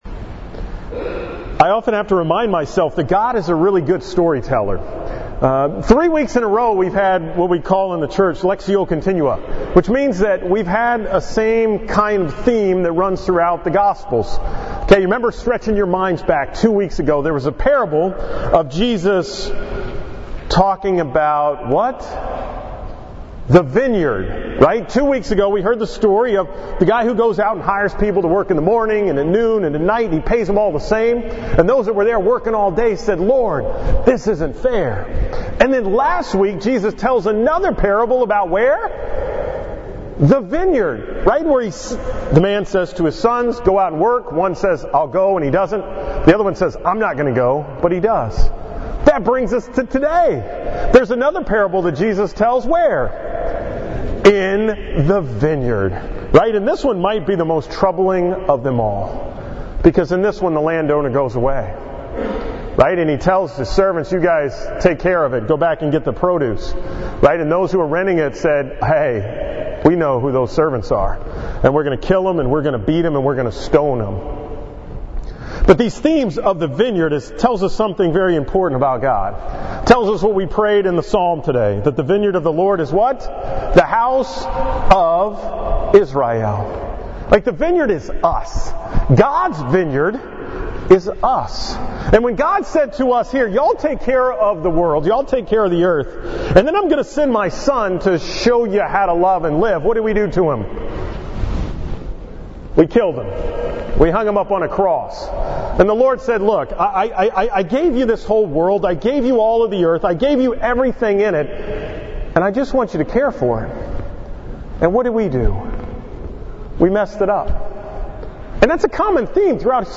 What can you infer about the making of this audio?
From the 9 am Mass at St. Mary's in Texas City on October 8, 2017